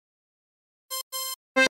描述：滤镜刺痛电子琴恍惚合成器
Tag: 137 bpm Electronic Loops Synth Loops 301.94 KB wav Key : Unknown